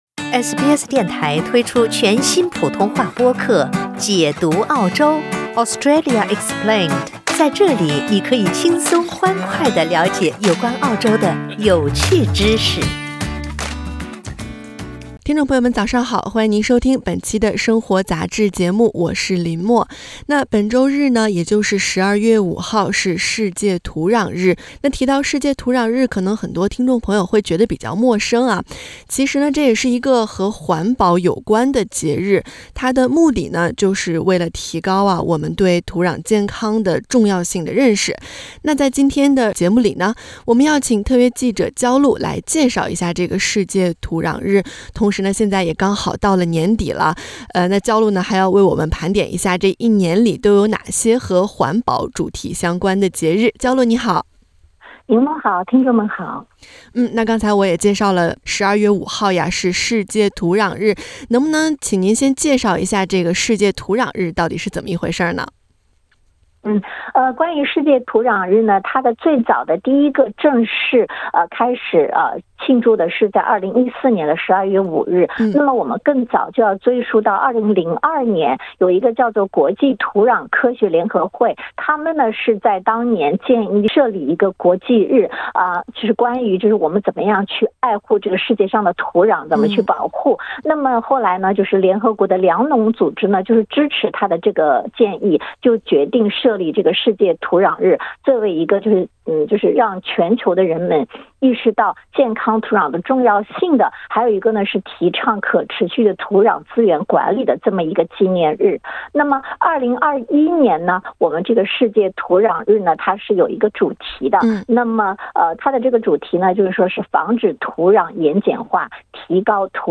世界主要环保纪念日 2 月 2 日 世界湿地日 3 月 22 日 世界水日 3 月 23 日 世界气象日 4 月 22 日 世界地球日 5 月 22 日 国际生物多样化日 6 月 5 日 世界环境日 6 月 8 日 世界海洋日 7 月 11 日 世界人口日 10 月 6 日 世界粮食日 12月5日 世界土壤日 您可以点击图片收听采访。